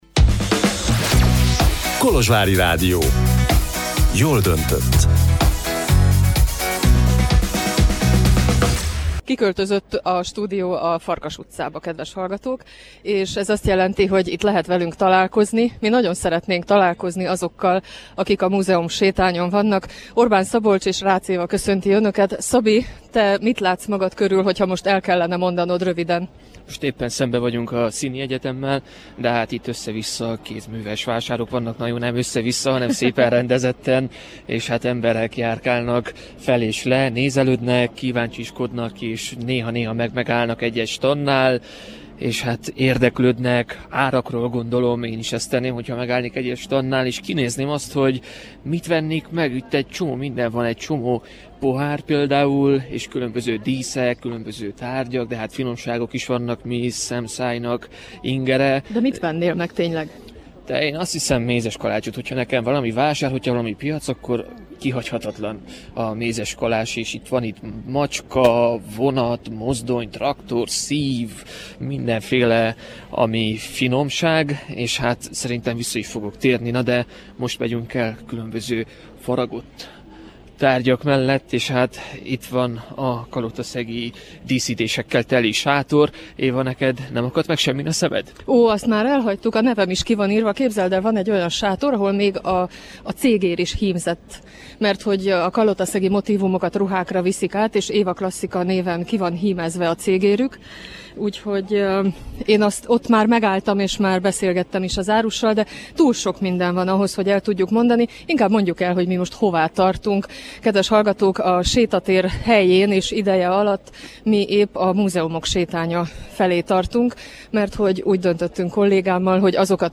Tartson velünk a hangos sétán a lejátszóra kattintva!